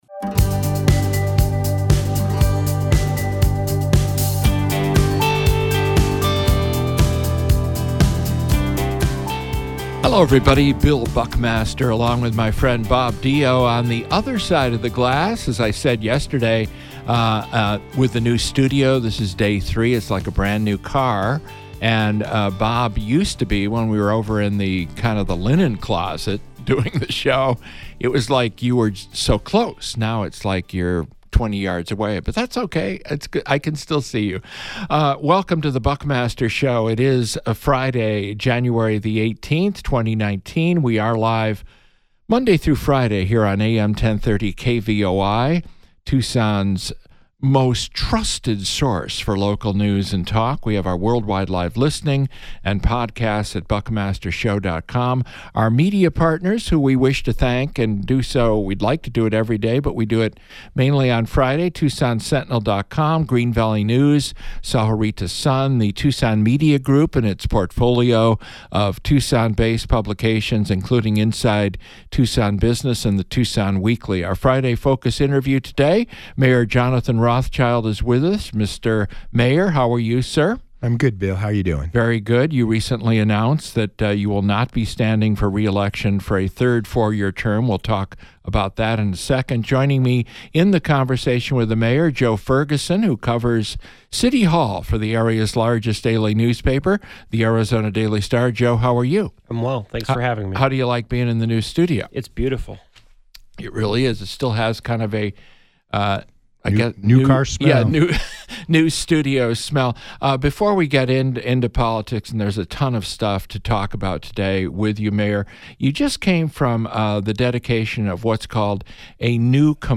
The Friday Focus interview features an extended edition with Tucson Mayor Jonathan Rothschild.